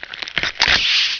rat_headPop.WAV